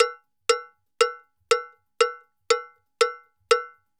Campana_Salsa 120_1.wav